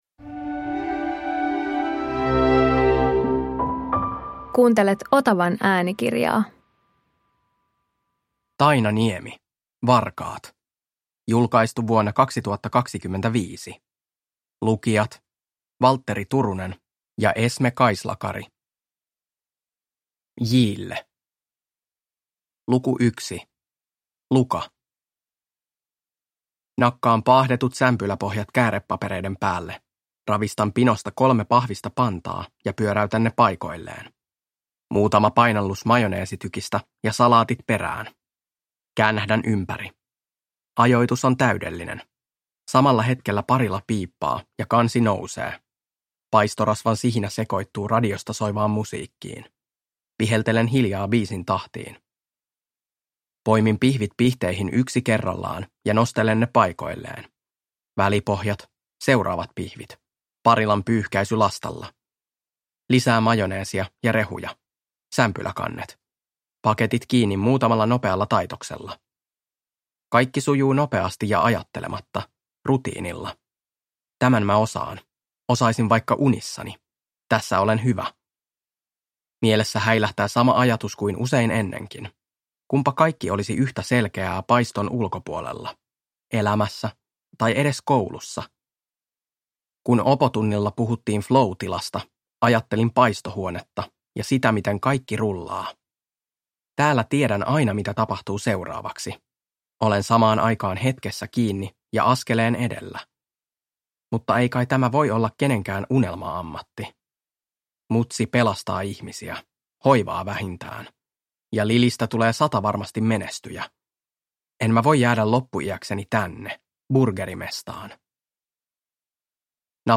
Varkaat – Ljudbok